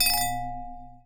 chime_bell_10.wav